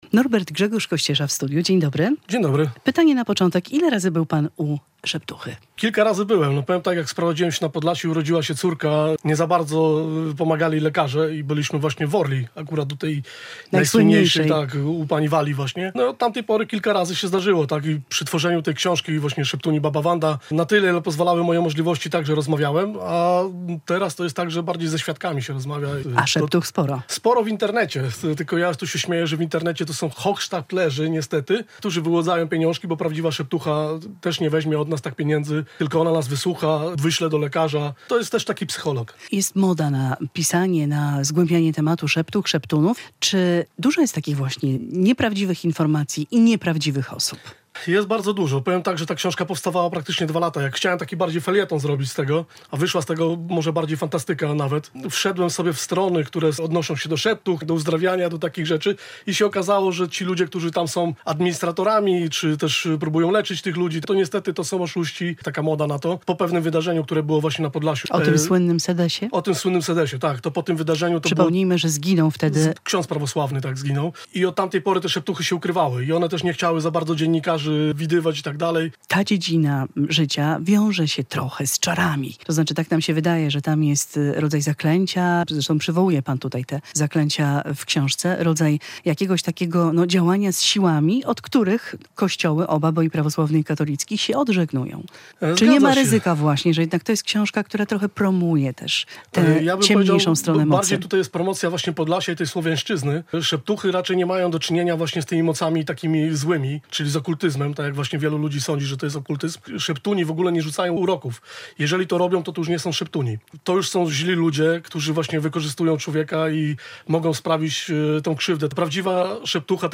Radio Białystok | Magazyny | Podróże po kulturze | Książka "Szeptuni.